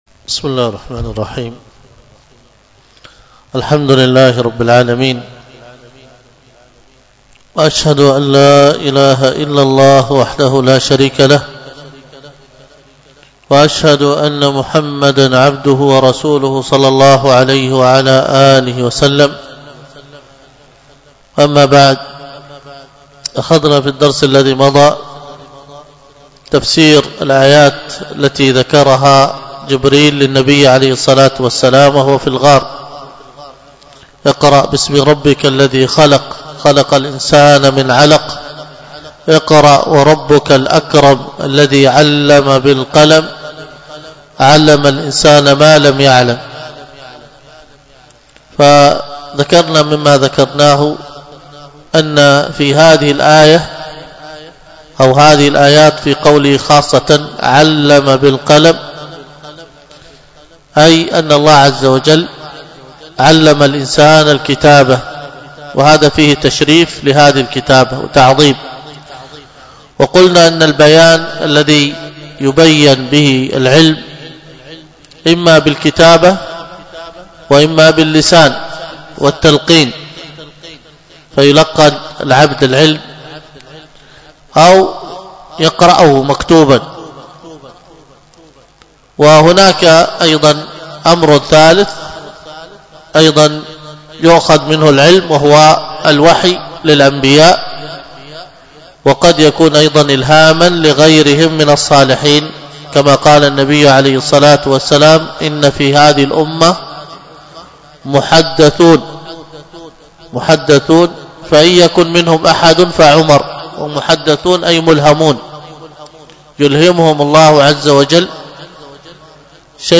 الدرس في شرح كتاب الطهارة 9، الدرس التاسع (ولا يجوز تمويه السقوف بالذهب والفضه ... والثاني من يستحل الميتات كعبدة الأوثان والمجوس).